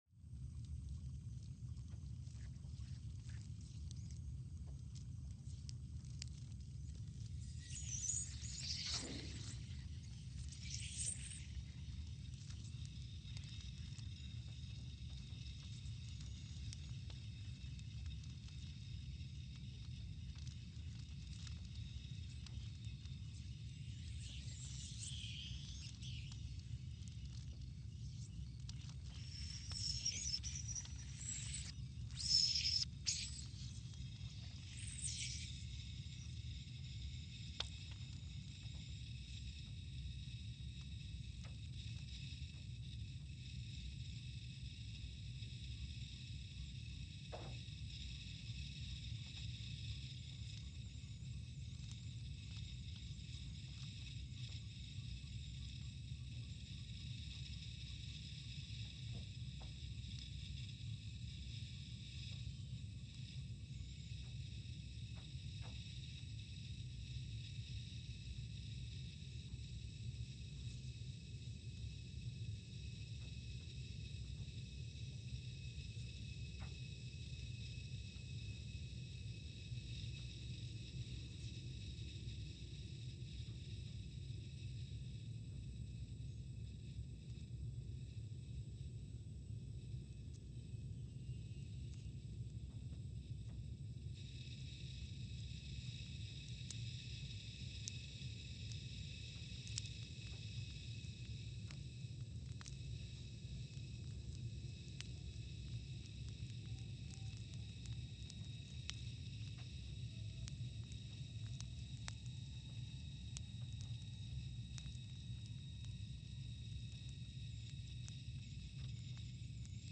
Scott Base, Antarctica (seismic) archived on September 26, 2020
Station : SBA (network: IRIS/USGS) at Scott Base, Antarctica
Speedup : ×500 (transposed up about 9 octaves)
Loop duration (audio) : 05:45 (stereo)